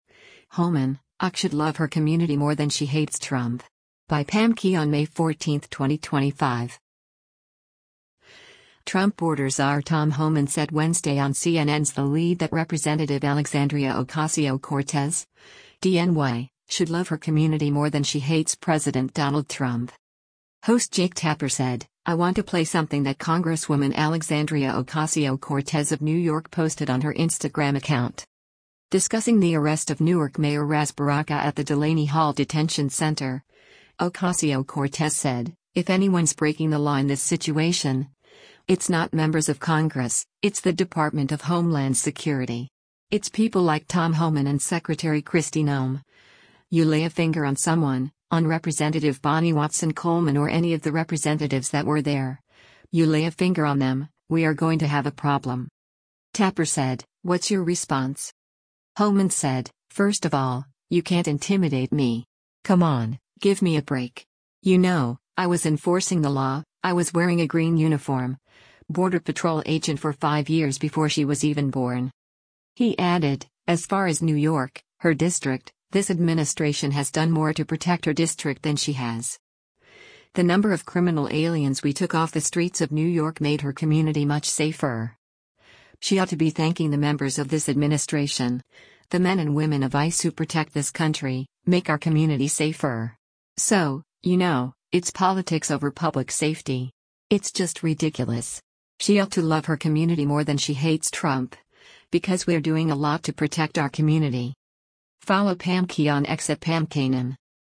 Trump border czar Tom Homan said Wednesday on CNN’s “The Lead” that Rep. Alexandria Ocasio-Cortez (D-NY) should “love her community more than she hates” President Donald Trump.
Host Jake Tapper said, “I want to play something that Congresswoman Alexandria Ocasio-Cortez of New York posted on her Instagram account.”